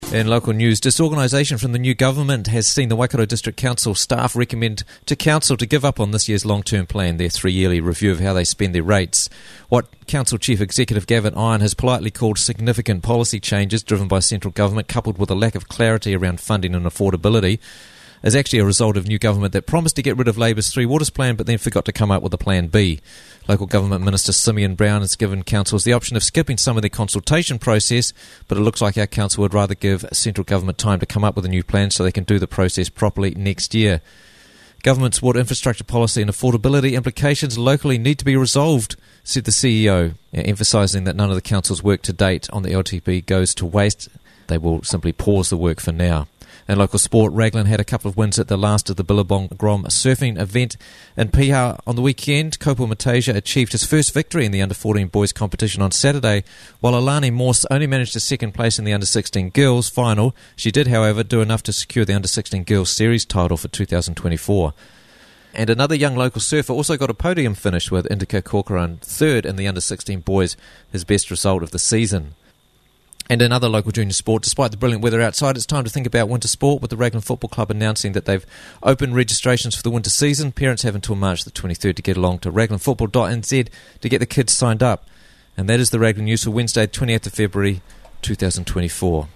Raglan News Bulletin